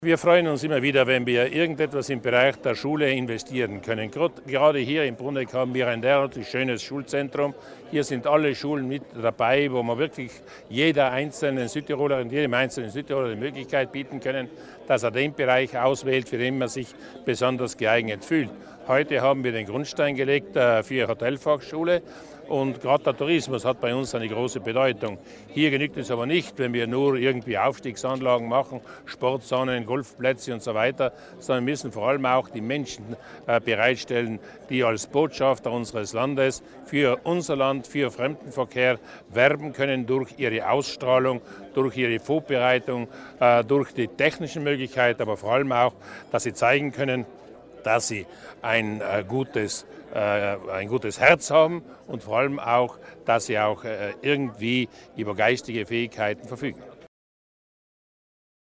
Landesrat Berger erklärt die Bedeutung der Ausbildung auch im Tourismusbereich